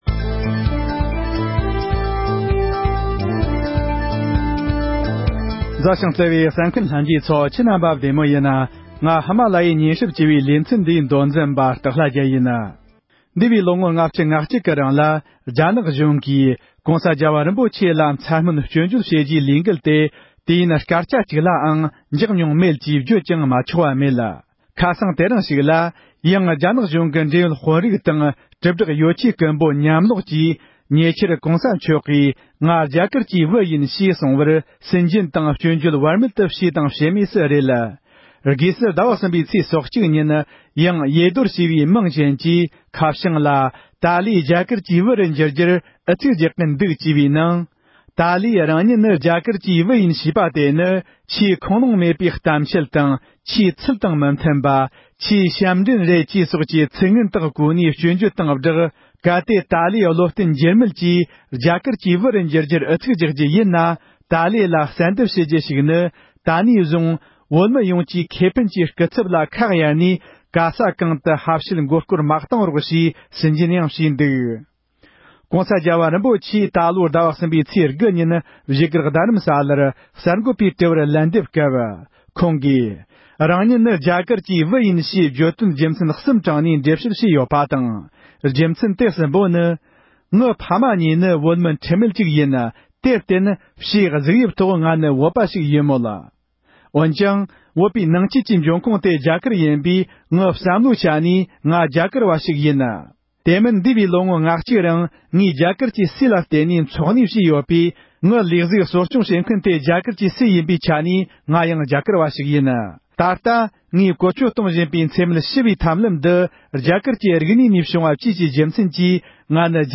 ཡེ་རྡོར་ཞེས་པའི་དགག་རྒྱག་རྩོམ་ཡིག་འབྲི་མཁན་དེ་ཡོད་མེད་སོགས་ཀྱི་ཐད་བགྲོ་གླེང༌།